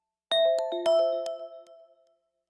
reply_send.ogg